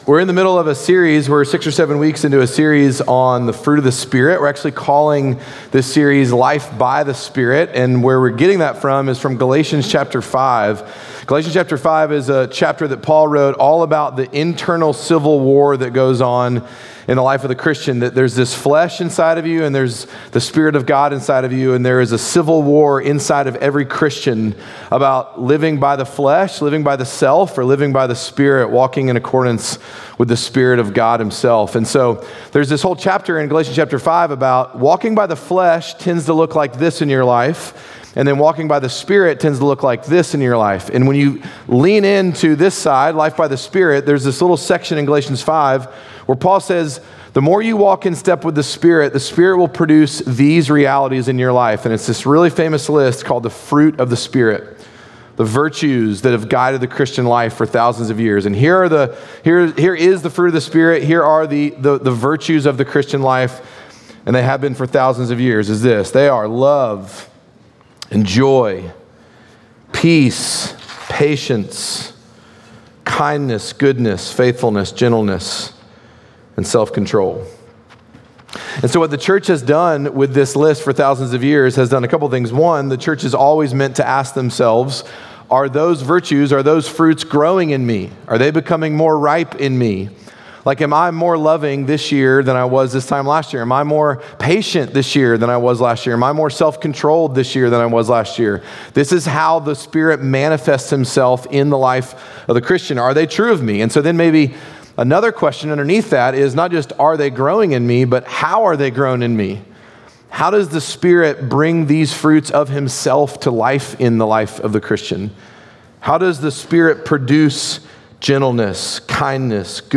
Midtown Fellowship 12 South Sermons Life By The Spirit: Goodness Jul 06 2025 | 00:39:18 1x 00:00 / 00:39:18 Subscribe Share Apple Podcasts Spotify Overcast RSS Feed Share Link Embed